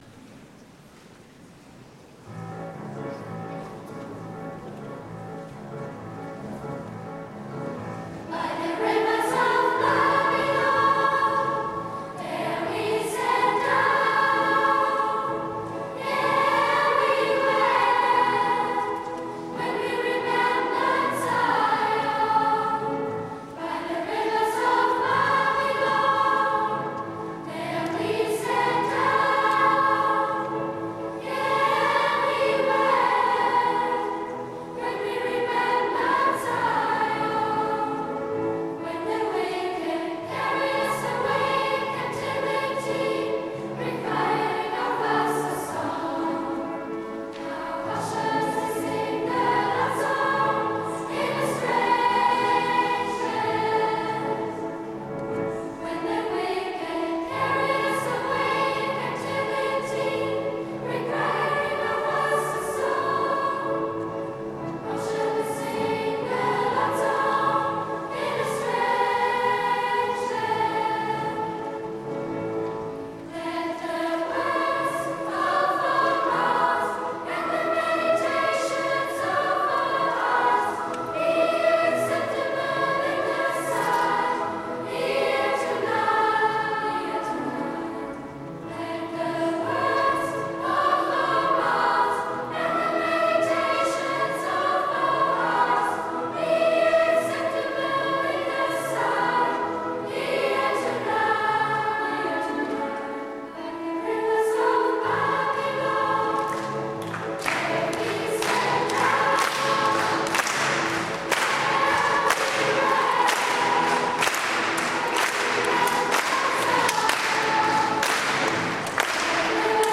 Lieder Kinder- und Jugendchor Lieder Unerh�rt Lieder Kleiner Chor Missa Criolla Zugabe Gloria und die "Moskow Nights" vom Konzert mit den R�dentalern
hier findet Ihr einige MP3s von unserem Lateinamerikanischen Konzert: Lieder Kinder- und Jugendchor Lieder Unerh�rt Lieder Kleiner Chor Missa Criolla Zugabe Gloria und die "Moskow Nights" vom Konzert mit den R�dentalern
LiederKinderundJugendchor.mp3